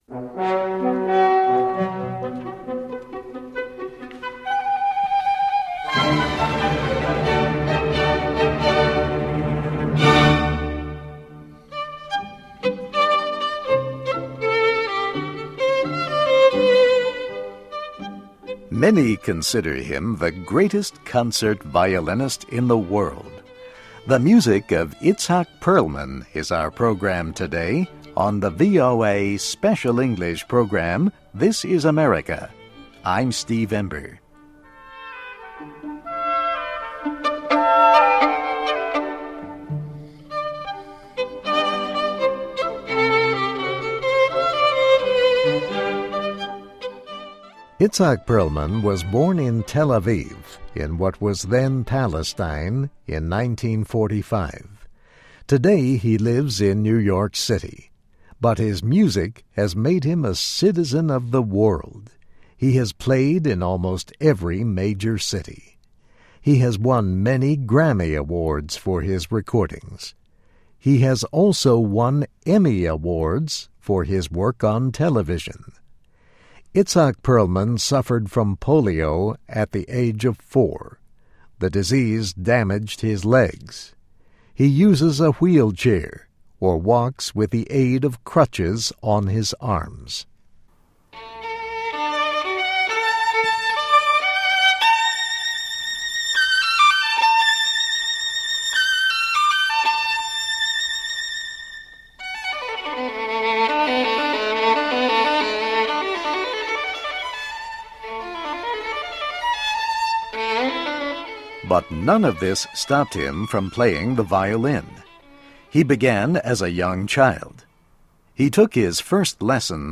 (MUSIC: Giovanni Viotti Violin Concerto No. 22 in A Minor)